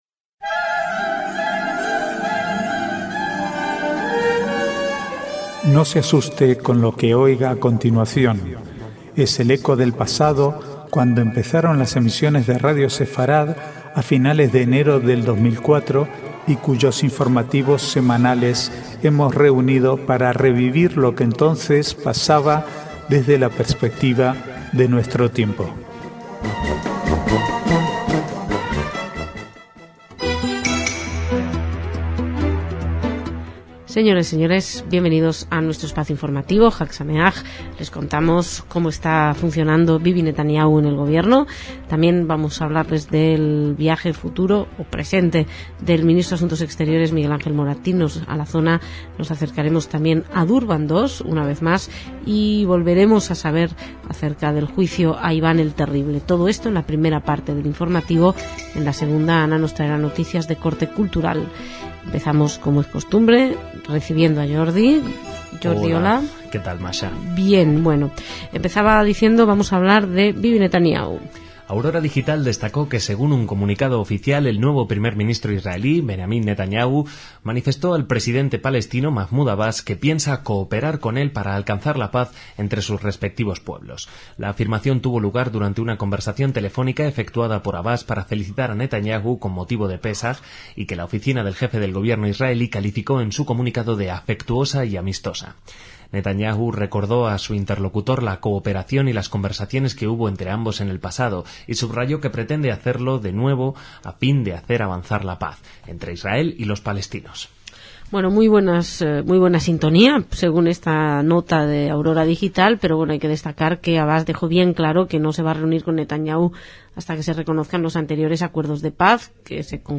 Archivo de noticias del 15 al 22/4/2009